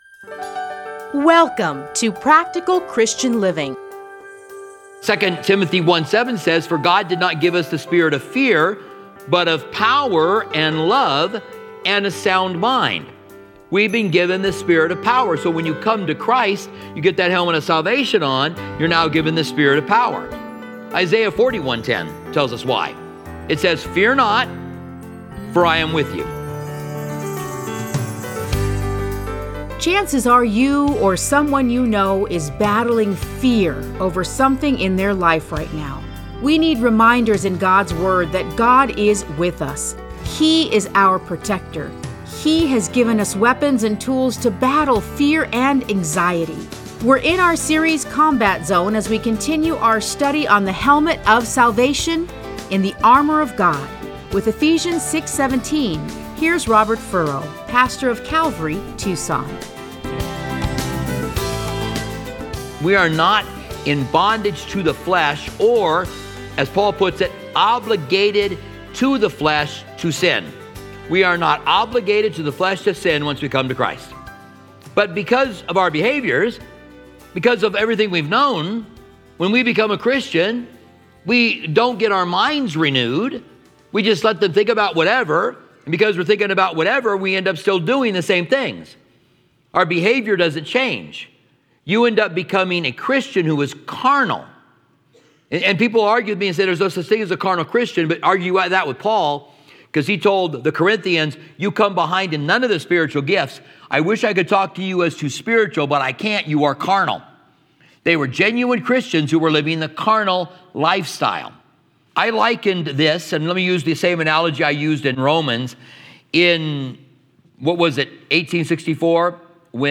Listen to a teaching from Ephesians 6:17.